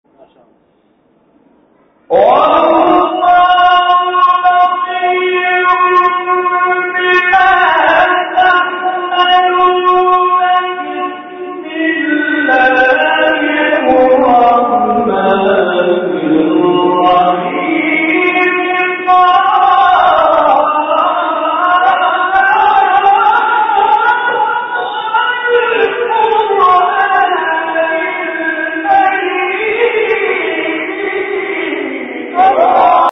گروه فعالیت‌های قرآنی: فرازهایی شنیدنی از قاریان ممتاز کشور را می‌شنوید.